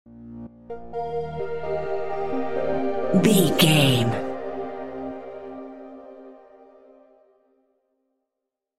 Epic / Action
Fast paced
Aeolian/Minor
aggressive
dark
intense
energetic
synthesiser
drum machine
breakbeat
synth bass